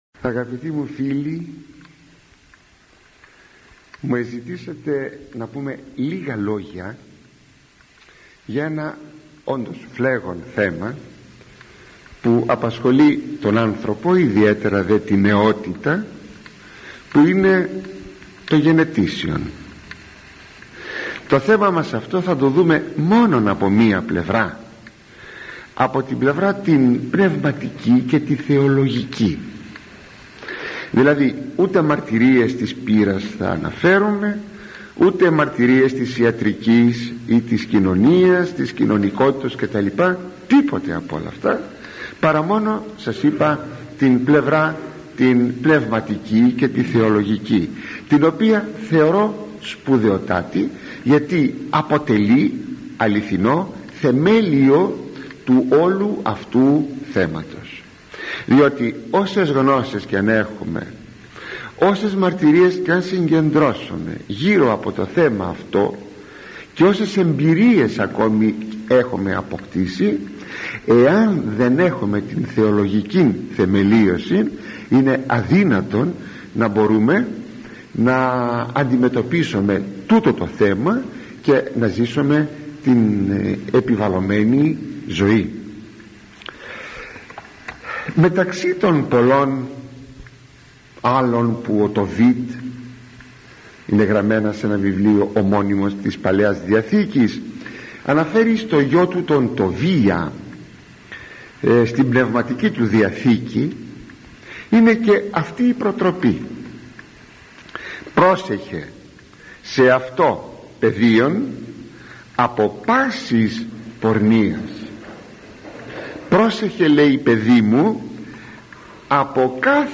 Το γενετήσιο ένστικτο – ηχογραφημένη ομιλία του Μακαριστού Αρχιμ.
Ο λόγος του ήταν πάντοτε μεστός, προσεγμένος, επιστημονικός αλλά συνάμα κατανοητός και προσιτός, ακόμη και για τους πλέον απλοϊκούς ακροατές του.